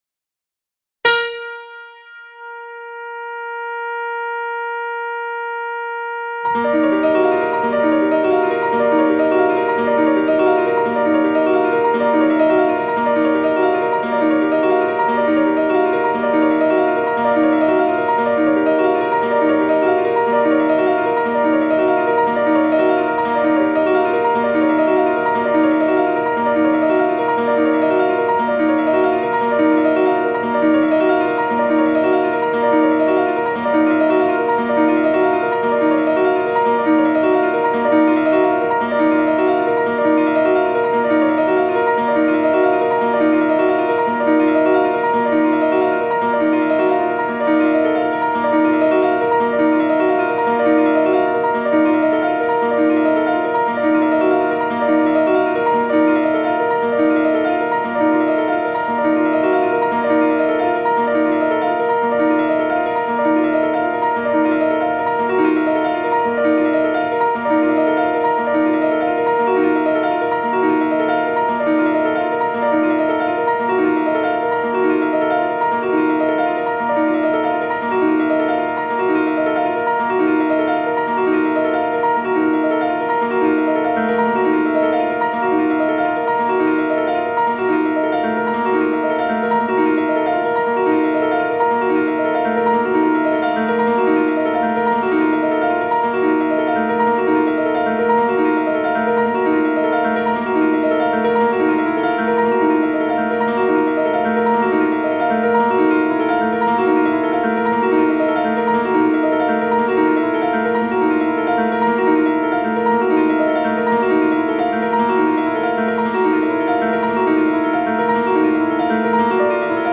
for piano [5’]